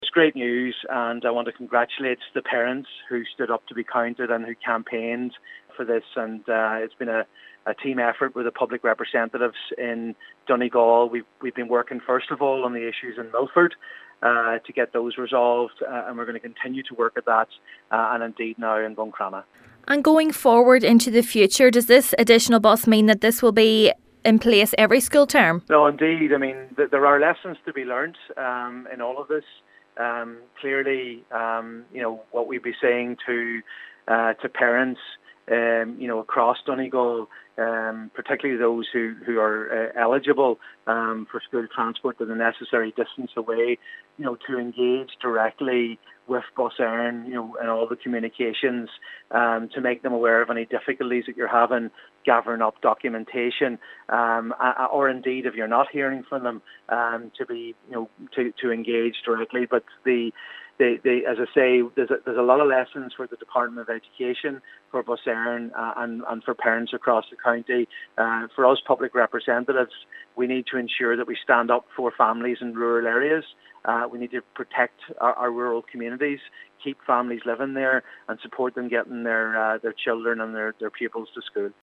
Senator Padraig MacLochlainn says today’s announcement is to be welcomed but lessons must be learnt from the difficulties faced by the parents in the South Inishowen, Termon and Kilmacrennan areas: